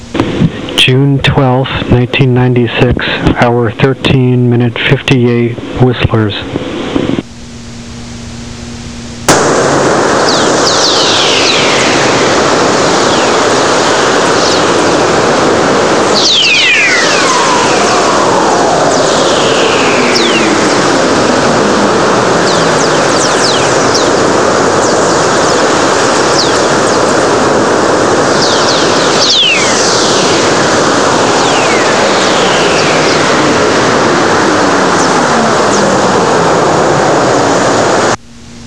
whistler3.wav